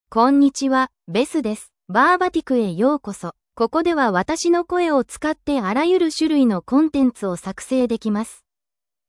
FemaleJapanese (Japan)
BethFemale Japanese AI voice
Voice sample
Listen to Beth's female Japanese voice.
Female
Beth delivers clear pronunciation with authentic Japan Japanese intonation, making your content sound professionally produced.